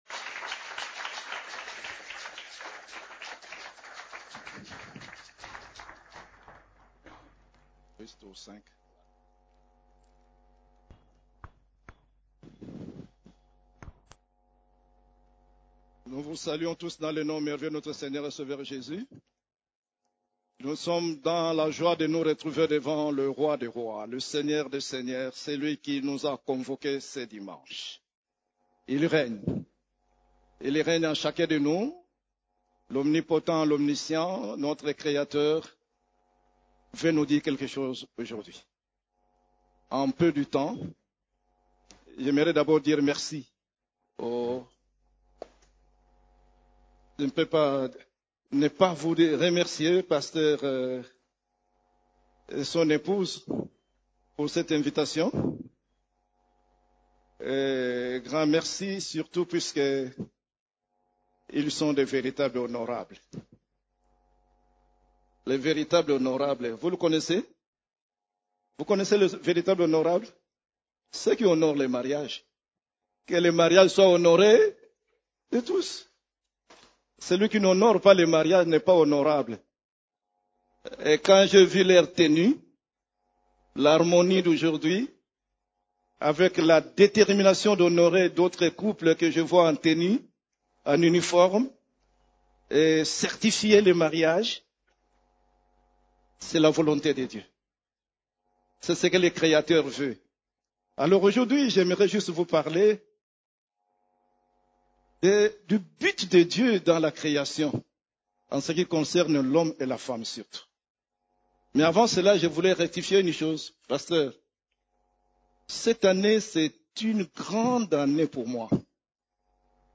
CEF la Borne, Culte du Dimanche, Le grand but de Dieu en créant l'homme et la femme